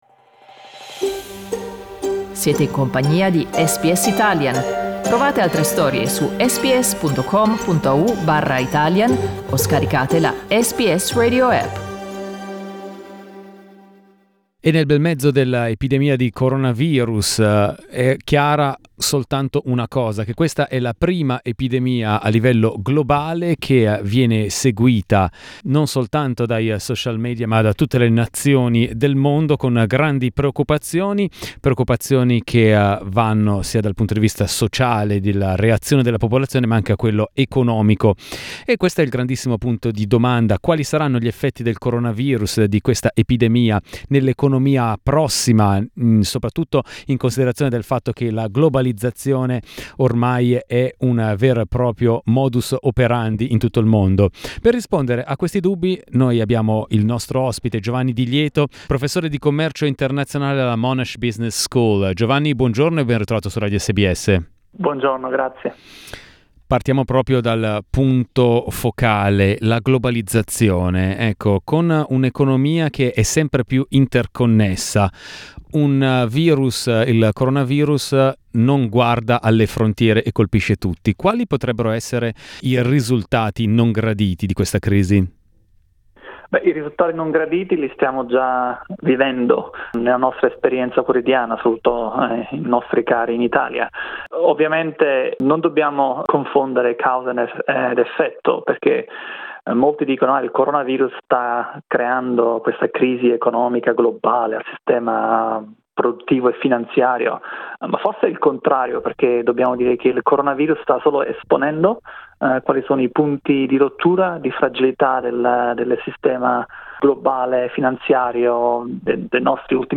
You can hear to his interview in Italian on the audio-player above.